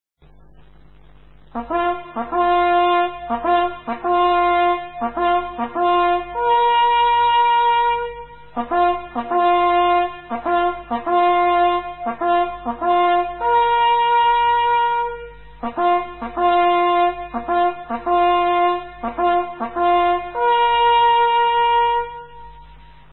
Kategorien: Soundeffekte